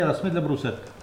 Localisation Soullans
Langue Maraîchin
Catégorie Locution